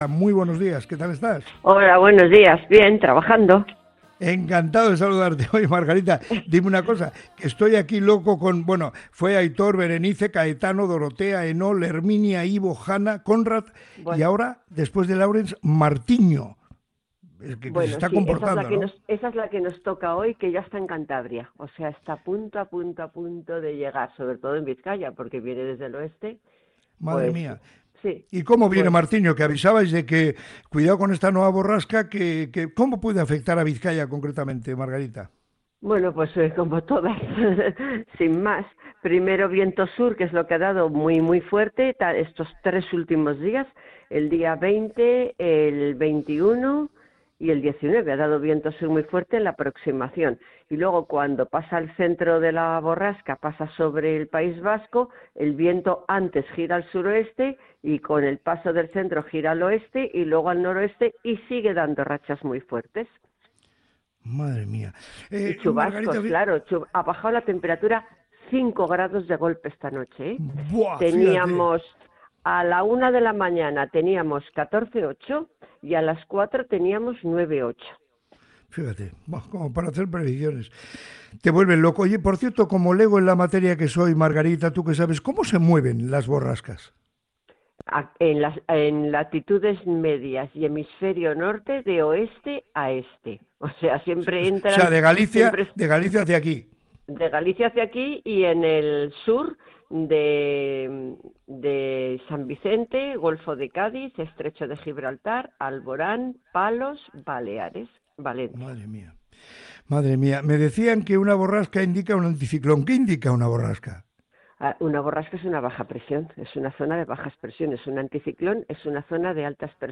ha explicado en el programa Moliendo Café de Radio Popular – Herri Irratia